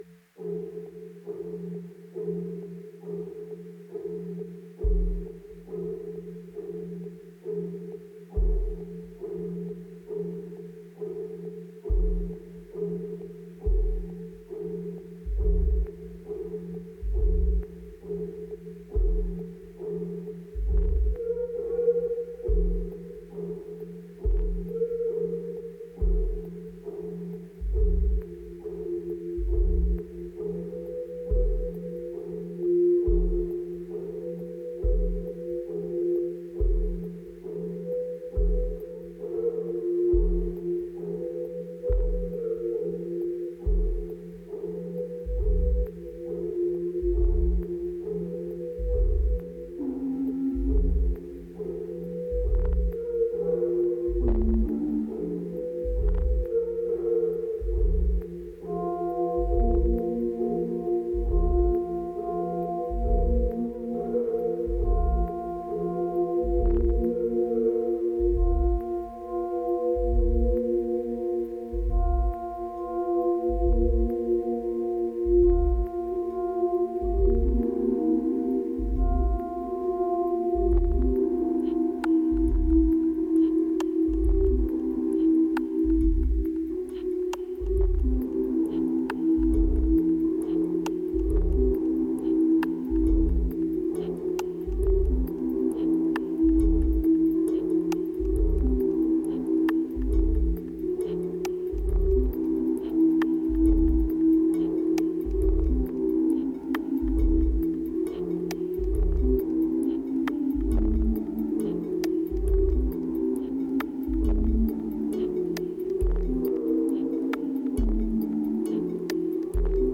2384📈 - 60%🤔 - 68BPM🔊 - 2017-04-08📅 - 191🌟